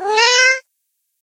meow1.ogg